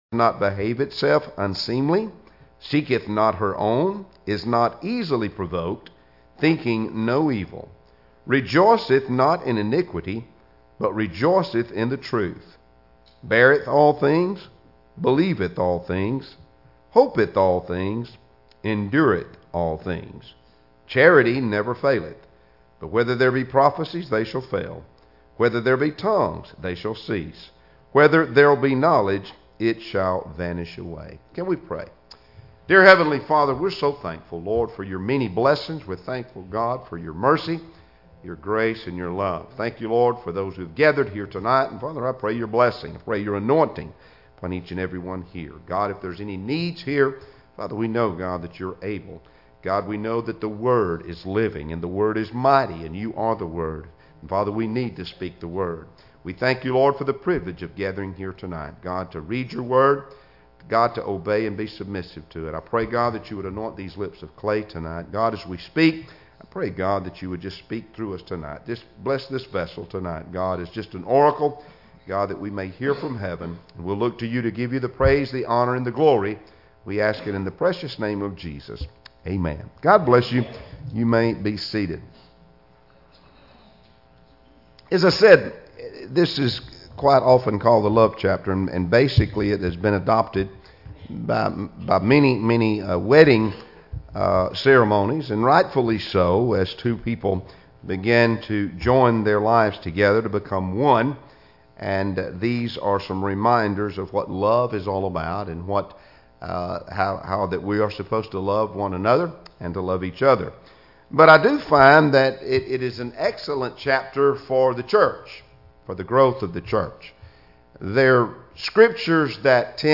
Passage: 1 Corinthians 13 Service Type: Wednesday Evening Services Topics